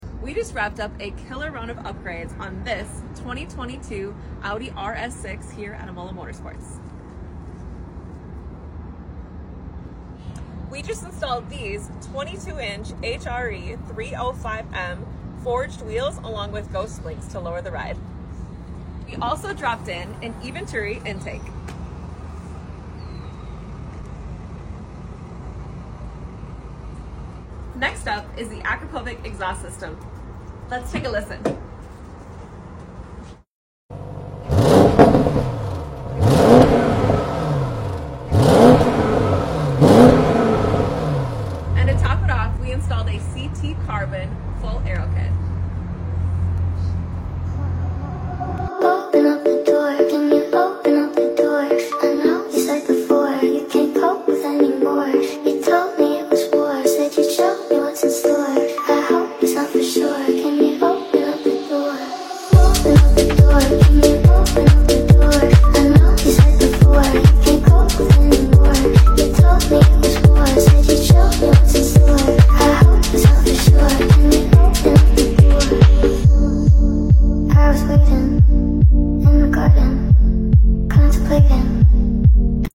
This Audi RS6 Avant is now meaner, lower, and louder🔥